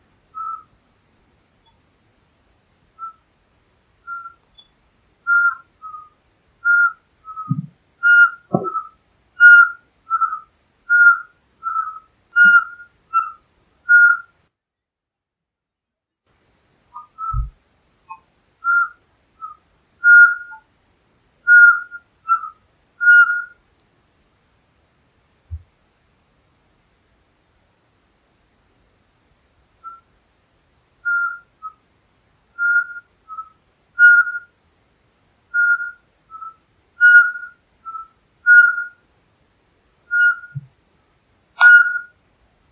Ukjent fuglelyd
Var på skitur i går kveld, Trøndelag.
Den var ganske langt unna, men tenke at jeg prøver å plystre på den, og jammen så kom den og satt seg i nærheten.
Opptaket høres litt metallisk ut, så det er vanskelig å avgjøre om det er en dompap eller en spurveugle.
Spurveugla lar seg lett lokke ved å imitere plystringen dens.
fuglelyd-ukjent.mp3